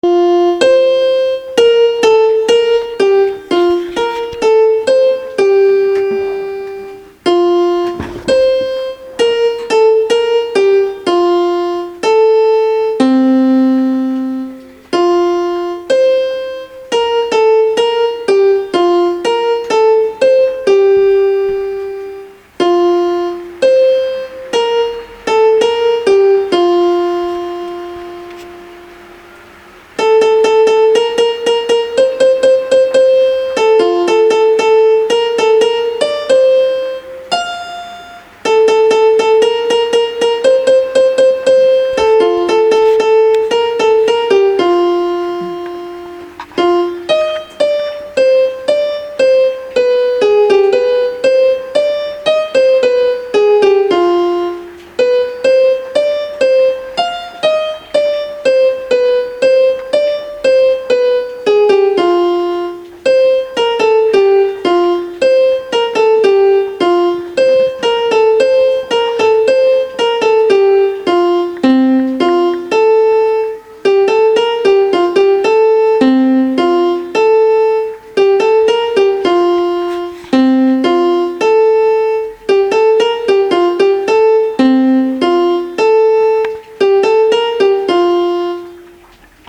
איכות ההקלטה - טובה. הנגינה קצת "נקודתית" מדי - עוד חצי שניה על הקלידים לא היה מזיק.